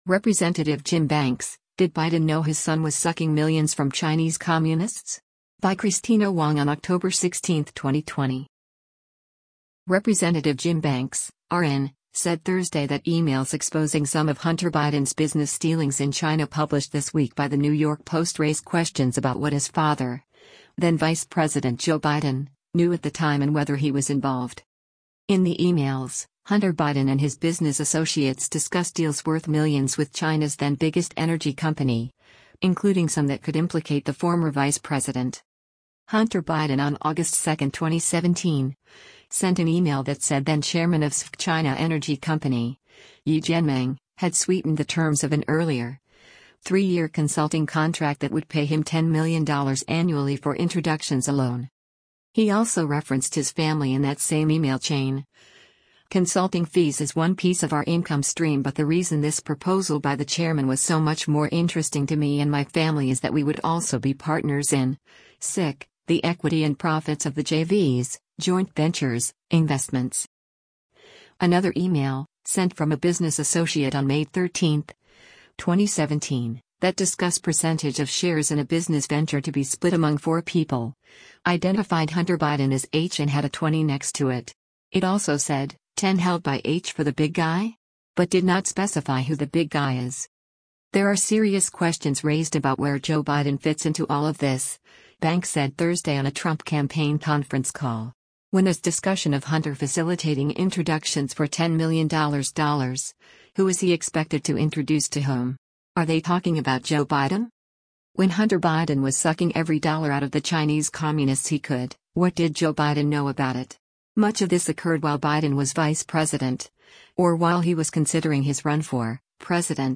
“There are serious questions raised about where Joe Biden fits into all of this,” Banks said Thursday on a Trump campaign conference call.